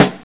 VINYL 4.wav